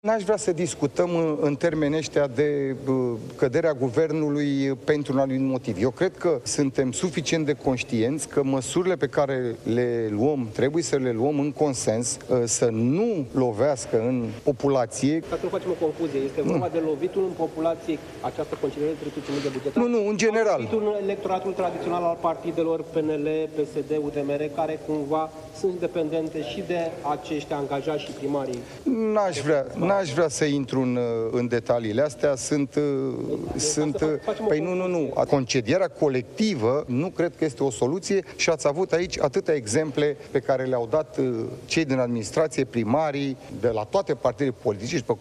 Daniel Zamfir a fost întrebat de jurnaliști cum afectează populația faptul că administrațiile locale ar fi „curățate” de 13.000 de oameni inutili și dacă nu cumva PSD și PNL protejează clientela politică, dependentă de locurile de muncă de la stat.